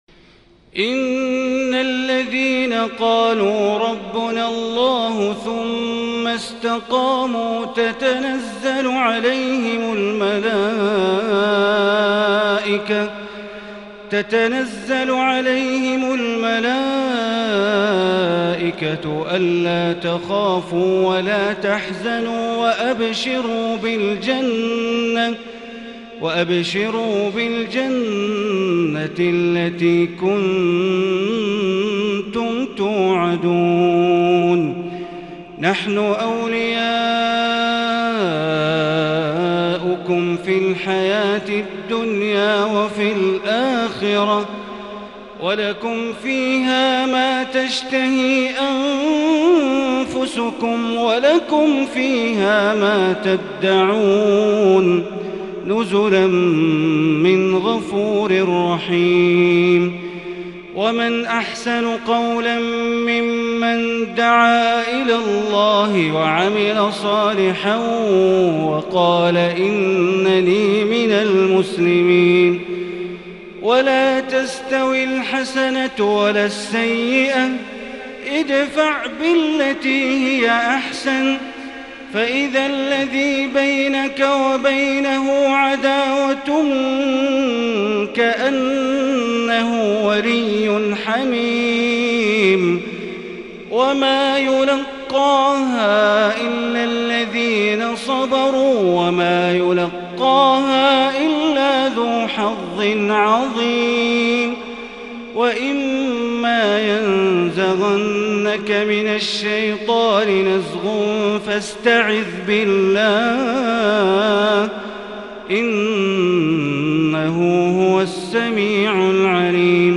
تهجد ليلة 26 رمضان 1441هـ من سورة فصلت (30) - سورة الشورى كاملة | tahajud prayer The 26rd night of Ramadan 1441H | from surah Fussilat and Ash-Shura > تراويح الحرم المكي عام 1441 🕋 > التراويح - تلاوات الحرمين